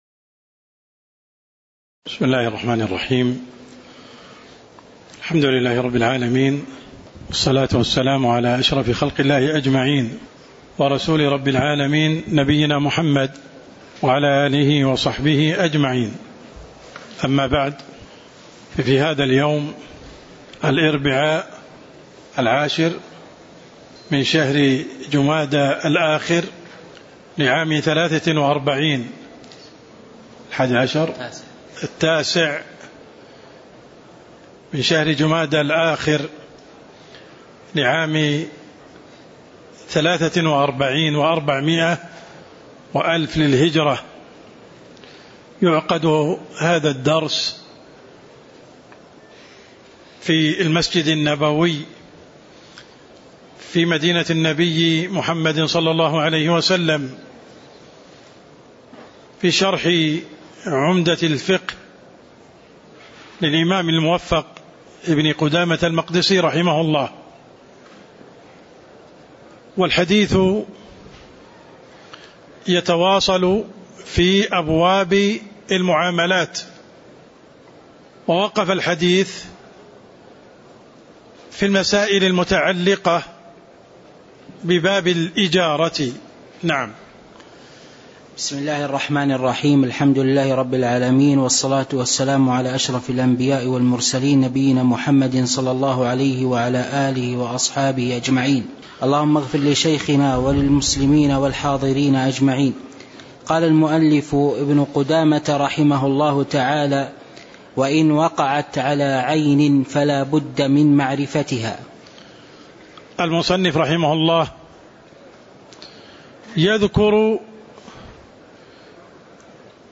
تاريخ النشر ٩ جمادى الآخرة ١٤٤٣ هـ المكان: المسجد النبوي الشيخ: عبدالرحمن السند عبدالرحمن السند قوله: وإن وقعت على عين فلابد من معرفتها (02)